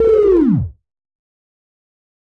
描述：录音机是一个简短的电子音响效果，由一个频率下降的音高弯曲组成。这个声音是用Cubase SX中的Waldorf Attack VSTi制作的。
Tag: 电子 SoundEffect中